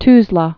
(tzlä)